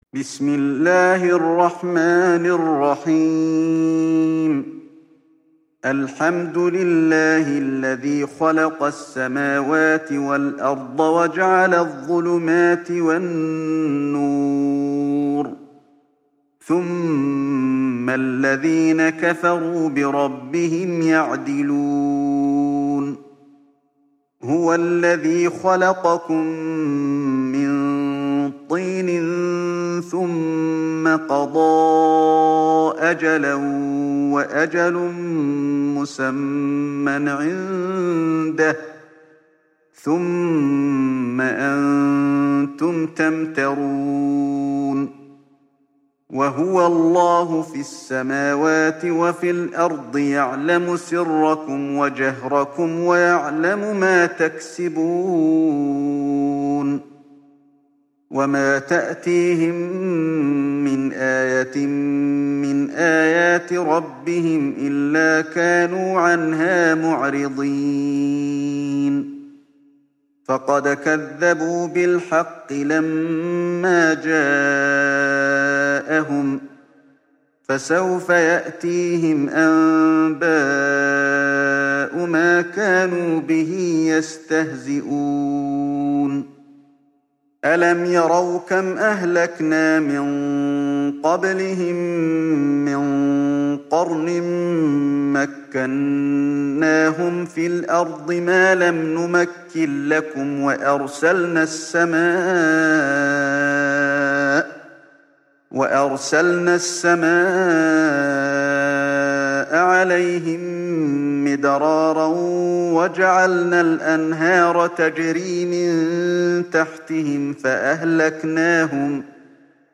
تحميل سورة الأنعام mp3 بصوت علي الحذيفي برواية حفص عن عاصم, تحميل استماع القرآن الكريم على الجوال mp3 كاملا بروابط مباشرة وسريعة